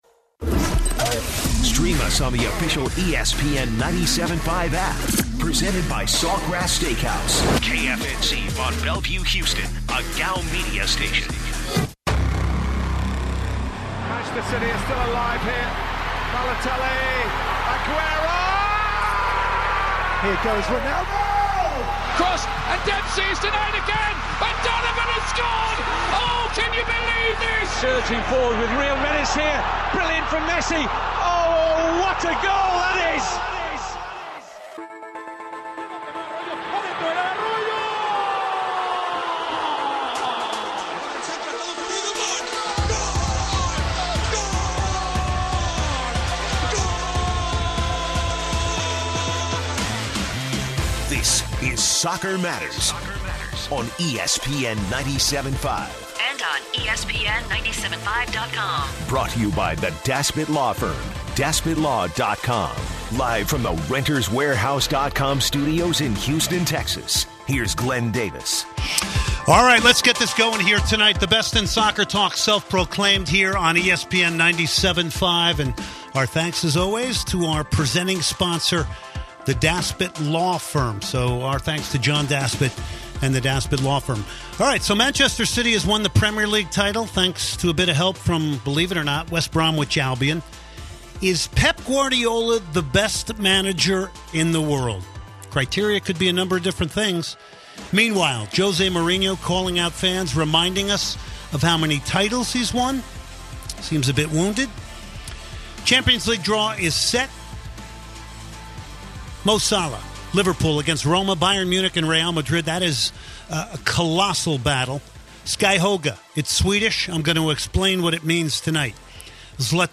takes calls to get perspectives and even exchanges some stories